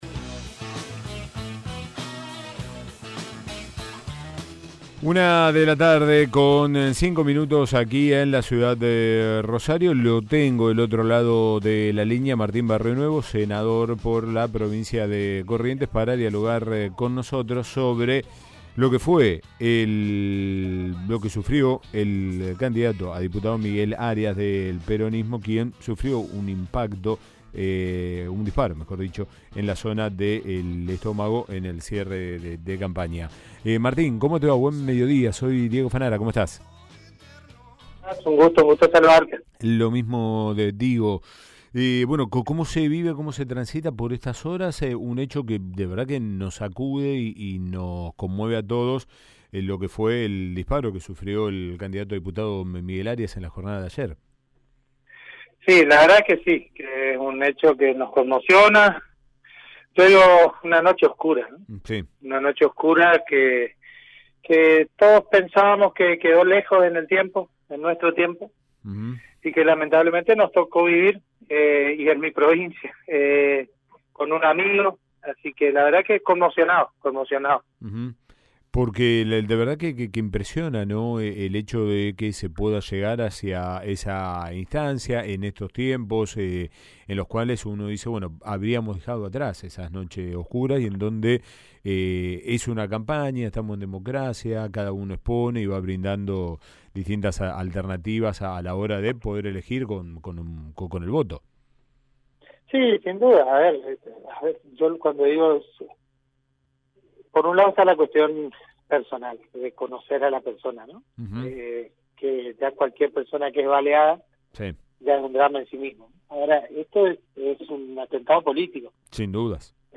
El diputado provincial de Corrientes Miguel Arias fue herido de un disparo de arma de fuego tras ser atacado por una persona mientras participaba de un acto de cierre de campaña del peronismo. AM 1330 dialogó al respecto con el Senador provincial de Corrientes Martín Barrionuevo.